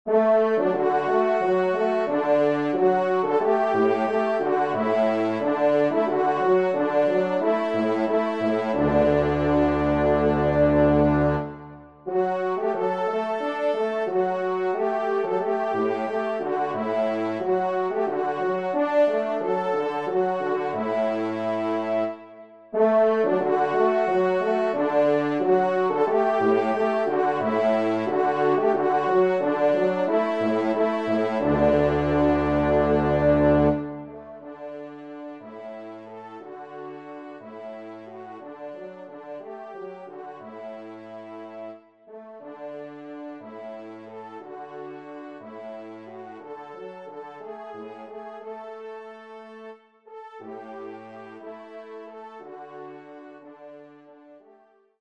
6e Trompe